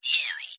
Audio S3. A highpass filtered version, usually biased toward Yanny.
ly_figure_highpass.wav